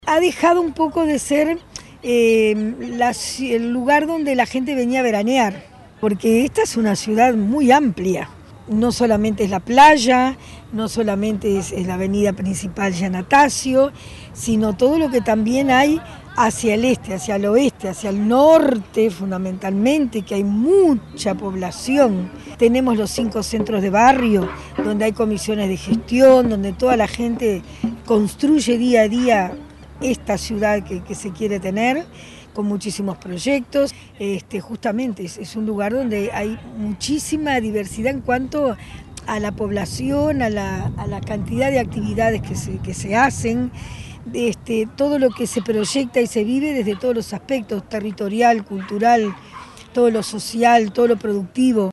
Ciudad de la Costa festejó sus 28 años con un acto en la Plaza Crottogini, ubicada en el km. 20.500 de la Avenida Giannattasio.
alcaldesa_sonia_misirian.mp3